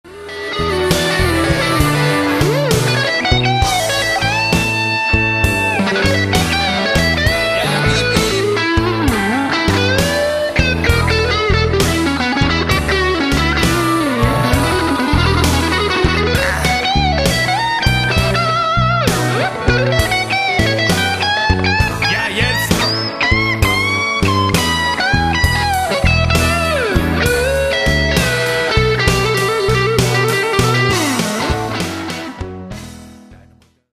Blues
Amateurvideo - live
Blues_Chorus.mp3